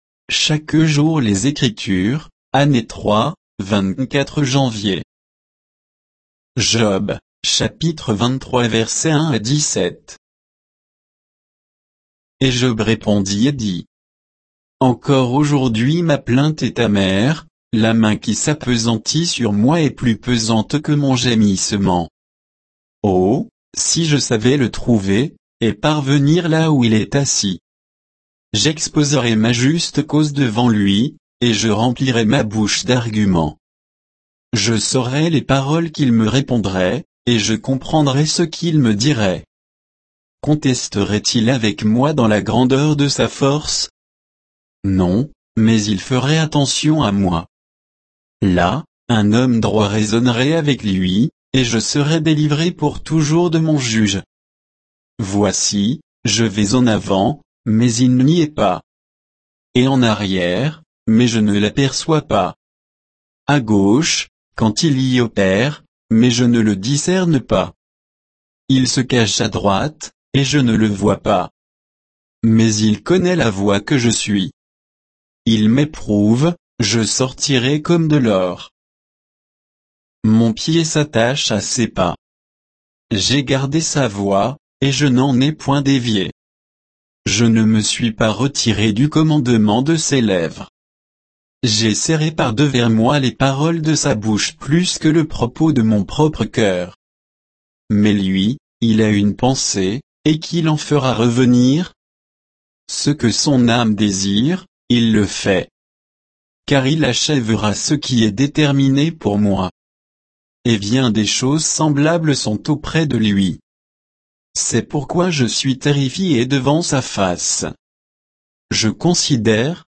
Méditation quoditienne de Chaque jour les Écritures sur Job 23